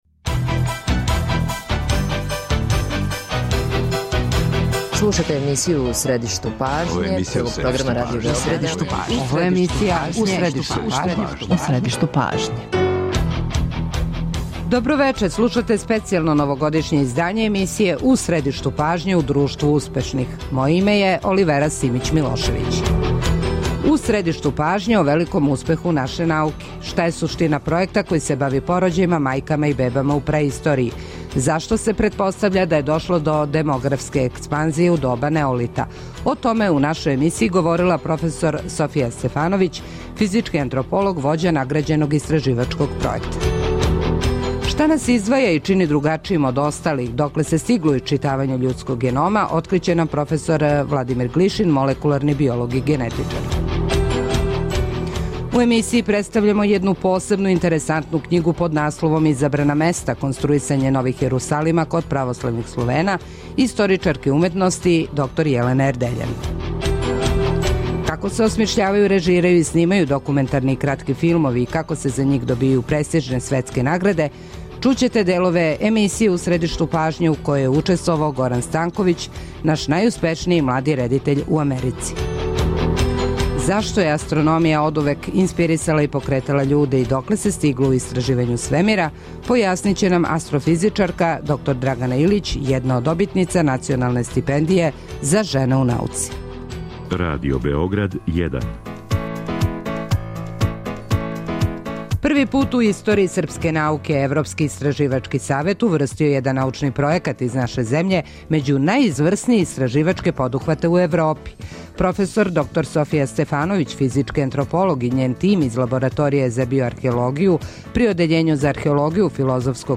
Чућете делове емисија "У средишту пажње" у којима смо трагали за одговорима на ова питања.